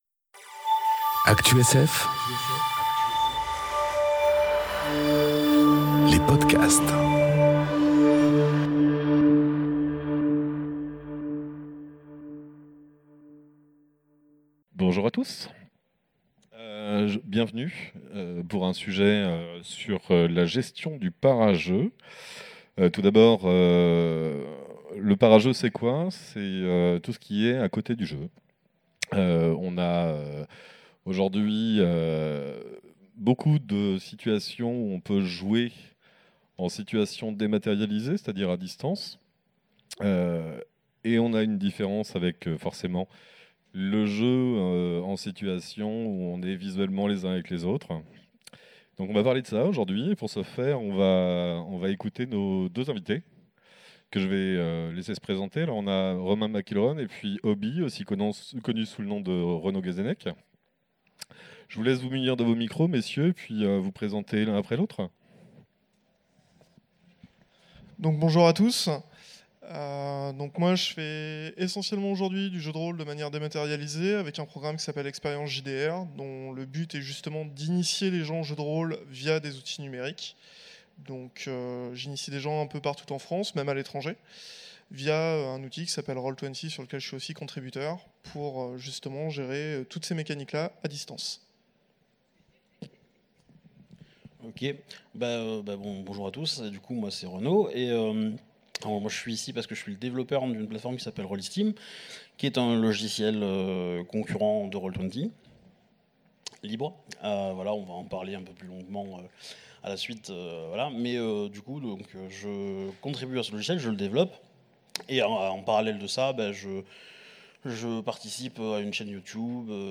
Conférence Gérer le para-jeu en situation dématérialisée enregistrée aux Utopiales 2018